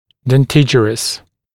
[den’tɪʤərəs][дэн’тиджэрэс]имеющий зубы, содержащий зубы